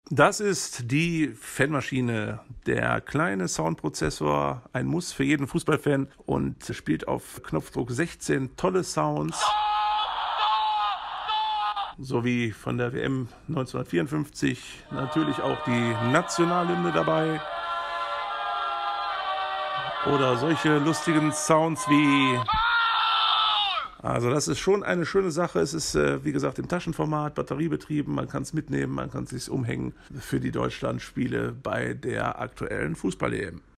Mit der Fan-Maschine könnt ihr alle Sounds erzeugen, die man beim Spiel braucht.
16 verschiedene Sounds auf Knopfdruck, zum Umhängen und Mitnehmen als echter Fan bei der EM. Vom Torjubel, über die Hymne bis zum Fangesang liefert euch die Fan-Maschine die richtige Atmosphäre.